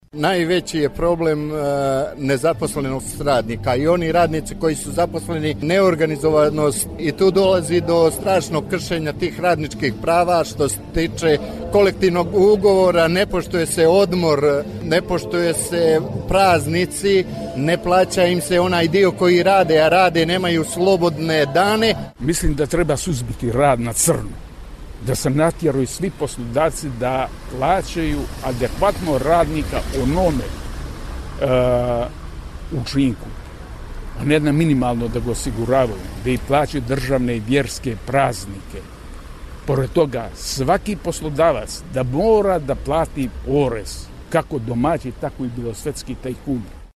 Sindikalni marš u Podgorici
Učesnici obije sindikalne povorke ukazuju na glavne probleme i pravce traženja rješenja: